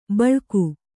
♪ baḷku